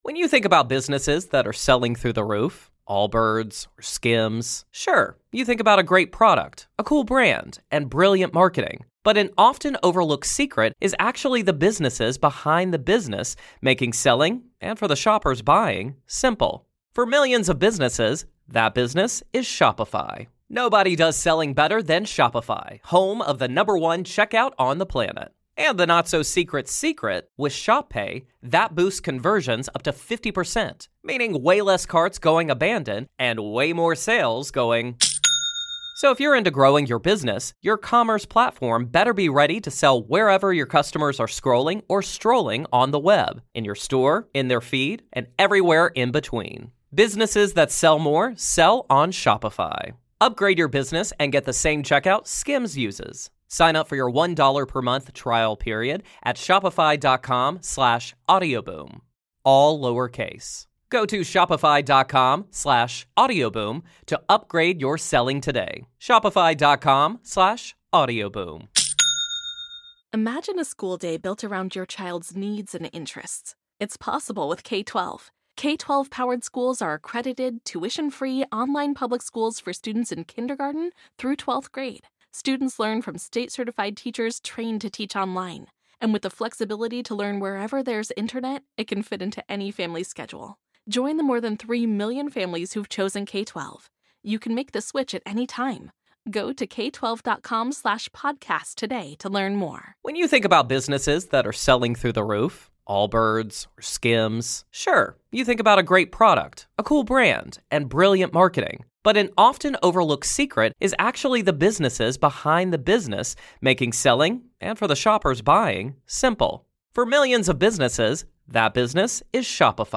Are they earthbound spirits, lost souls from the spirit world, or something even less human — perhaps extraterrestrial? In Part One of this classic Grave Talks conversation